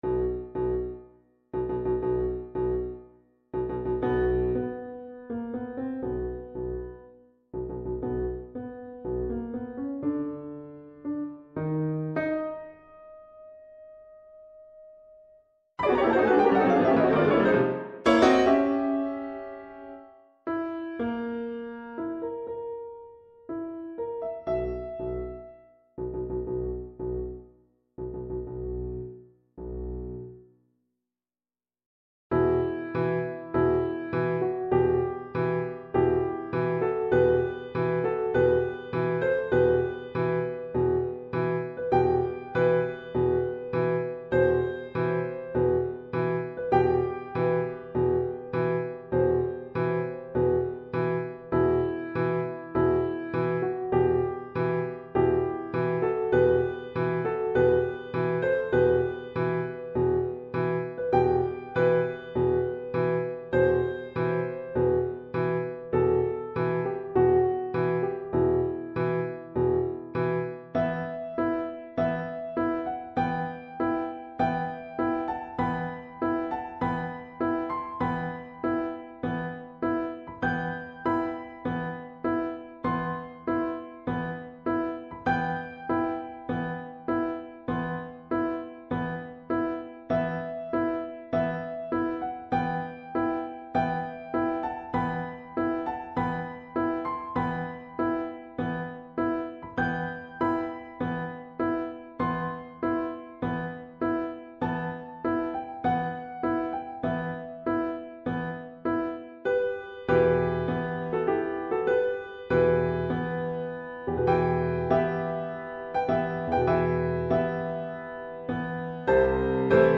specimen of a Marquesan dance:—